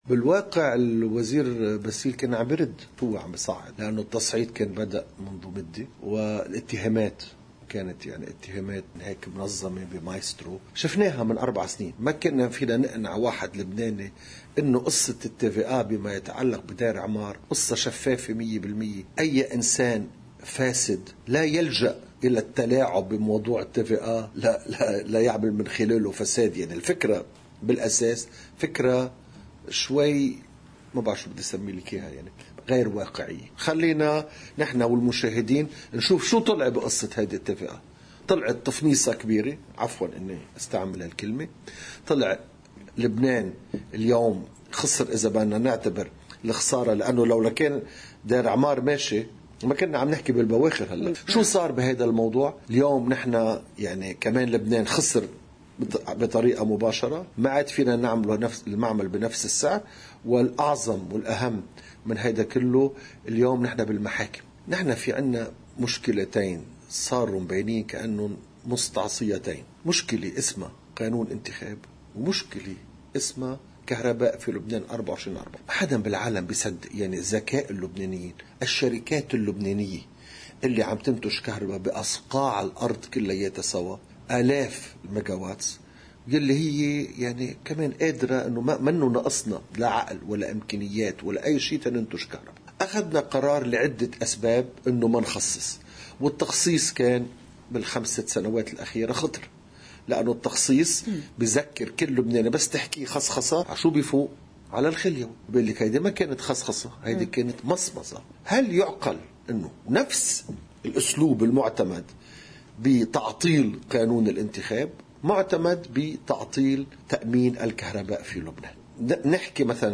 مقتطف من حديث الوزير السابق فادي عبود لقناة الـ”OTV”: